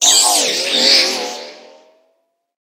Cri de Méga-Raichu X dans Pokémon HOME.
Cri_0026_Méga_X_HOME.ogg